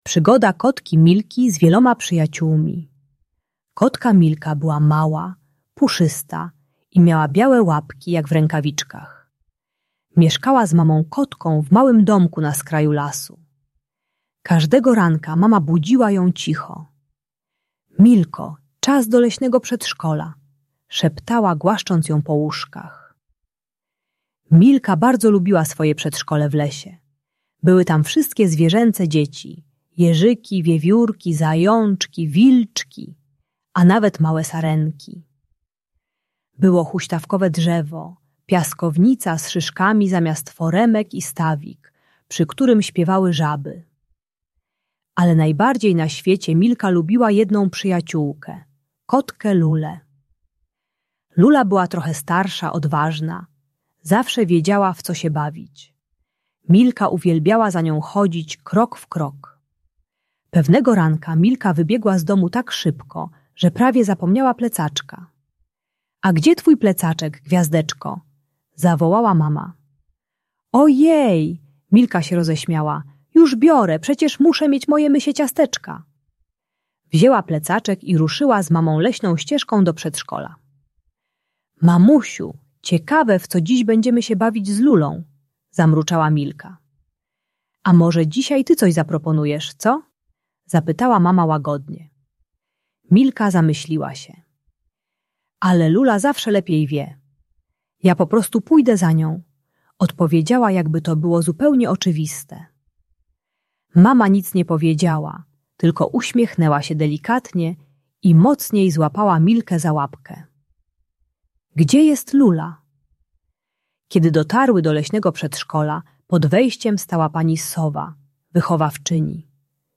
Przygoda kotki Milki - Przedszkole | Audiobajka